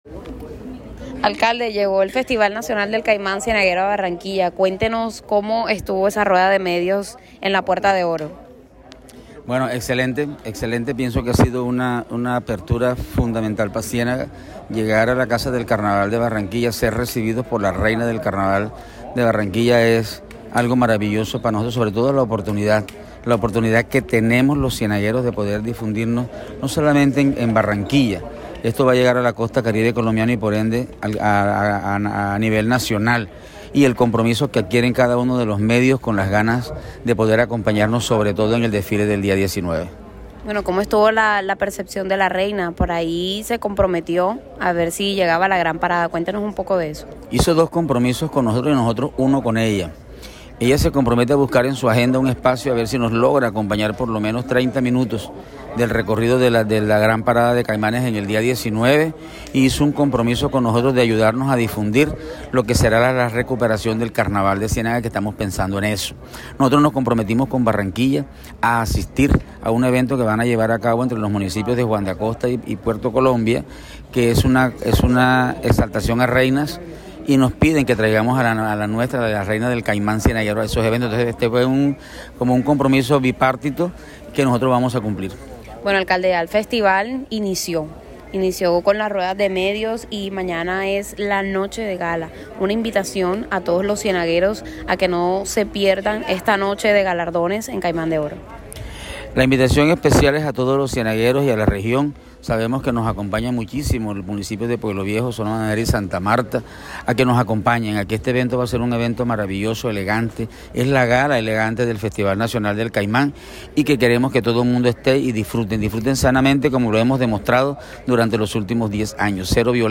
AUDIO-ALCALDE-DE-CIENAGA-online-audio-converter.com_.mp3